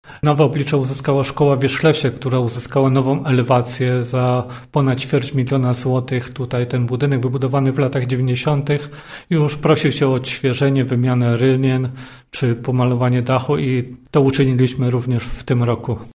Ten budynek, wybudowany w latach 90-tych, już prosił się o odświeżenie, wymianę rynien czy pomalowanie dachu i to zrobiliśmy – mówi wójt gminy Wierzchlas, Leszek Gierczyk.